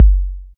DS19808Kick.wav